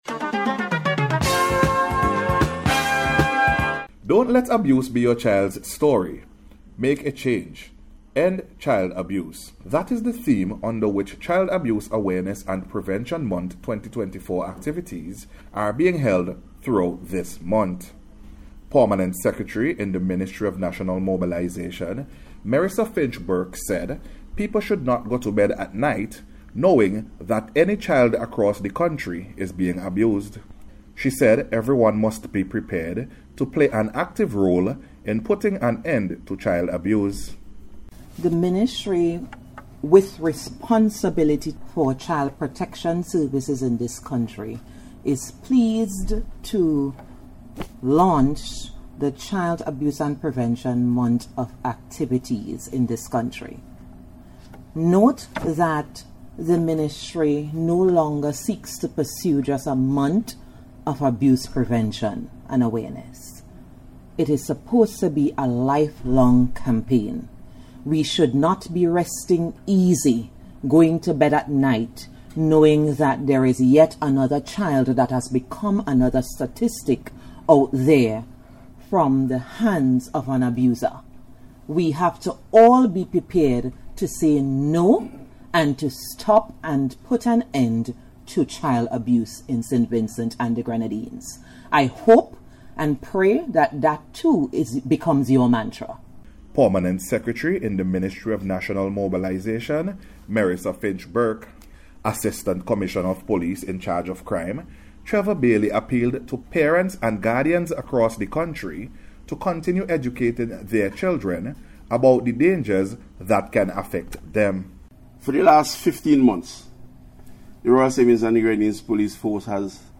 These appeals were made by different speakers during yesterday’s official launch of activities to commemorate Child Abuse Awareness and Prevention Month 2024.